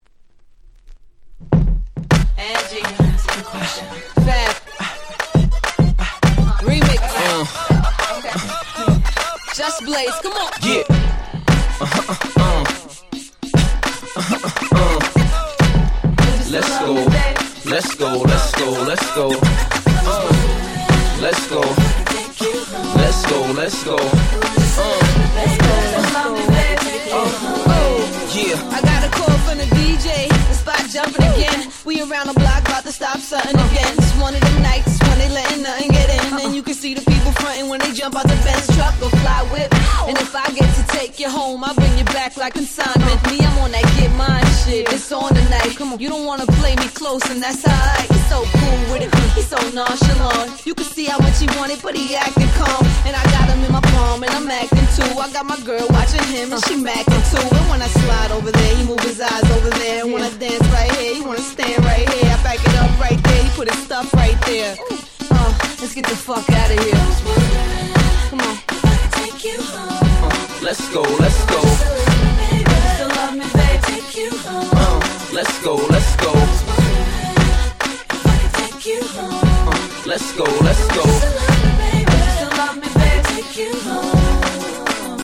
02' Smash Hit Hip Hop !!